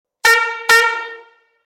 Buzinas a Ar para Bicicletas Simples
• 01 corneta;
• Intensidade sonora 130db;
• Acionamento através de bomba manual;